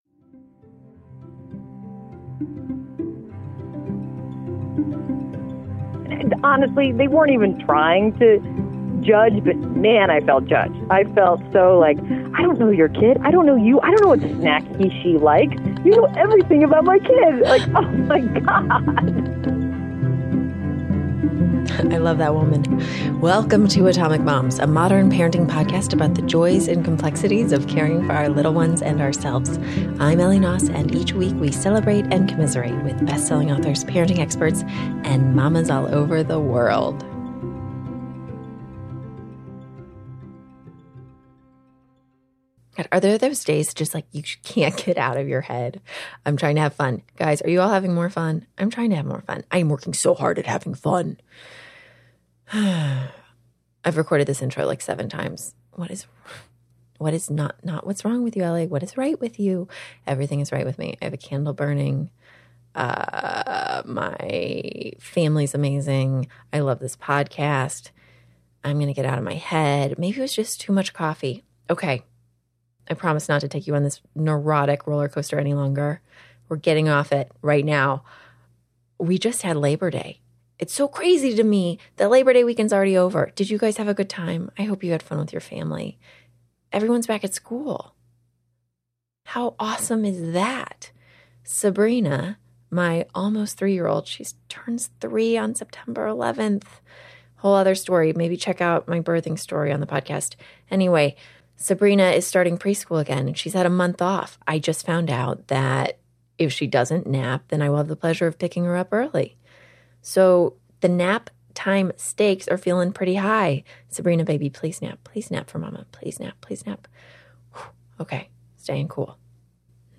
We're sharing our uncensored and funny mom-friend call with CNN anchor Kate Bolduan.